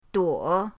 duo3.mp3